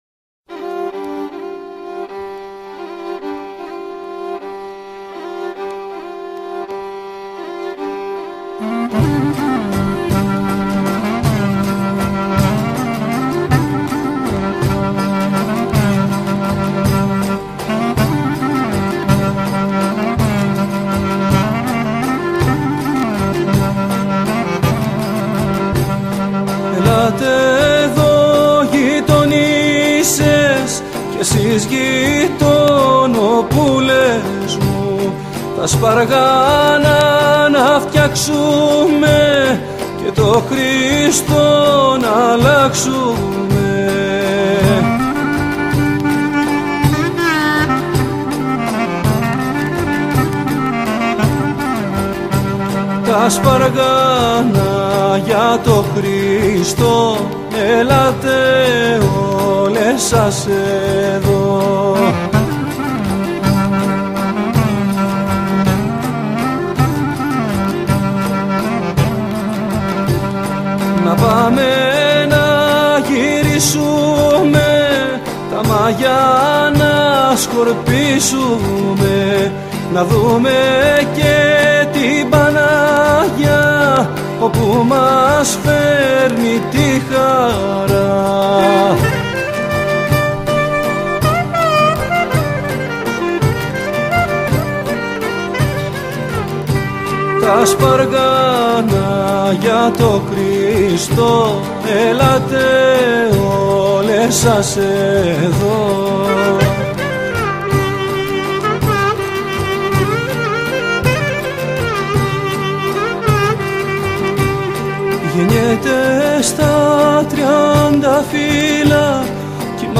Γονείς και παιδιά στο νηπιαγωγείο μας τραγουδούν και χορεύουν τα παραδοσιακά Χριστουγεννιάτικα κάλαντα !!!